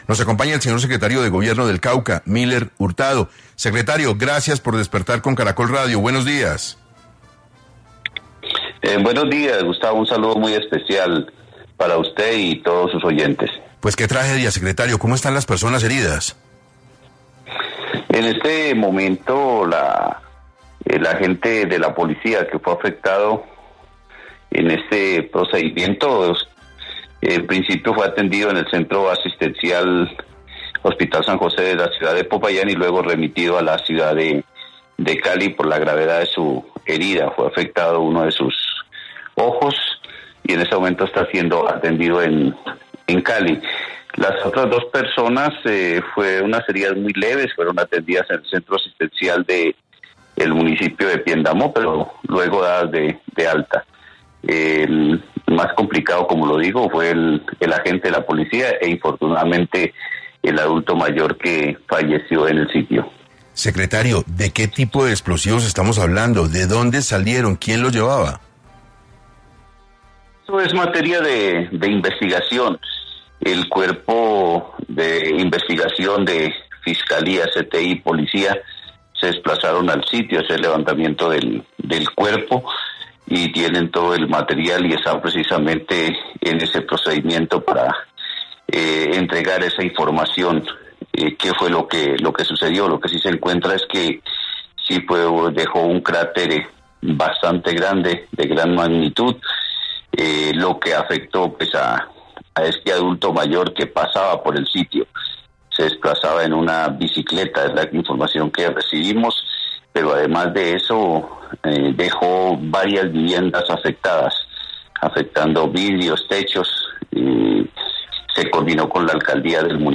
En 6 AM Hoy por Hoy, se conectó Miller Hurtado, secretario de Gobierno del Cauca, quien habló sobre la situación que se está viviendo luego de la explosión que hubo en este lugar y a su vez, por el estado de salud de los policías heridos.